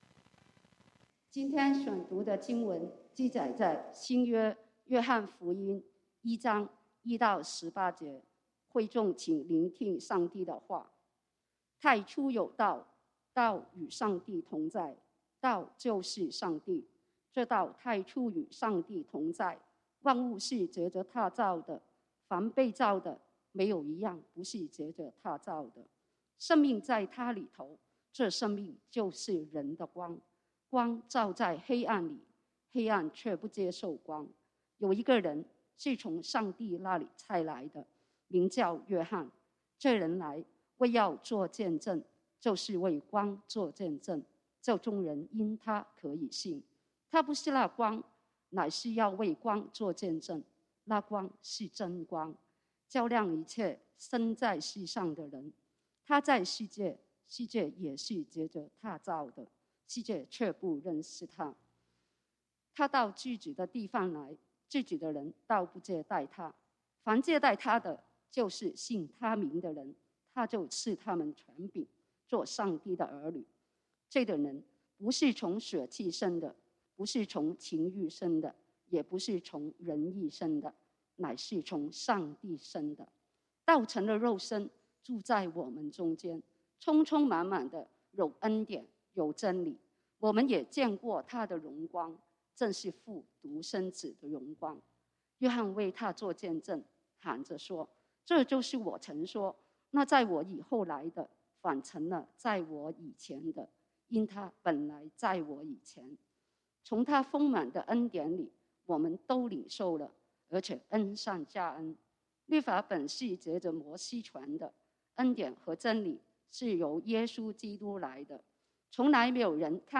講道經文：約翰福音 John 1:1-18 本週箴言：加拉太書 Galatians 2:20-21 我已經與基督同釘十字架，現在活着的不再是我，乃是基督在我裡面活着； 並且我如今在肉身活着，是因信上帝的兒子而活；祂是愛我，為我捨己。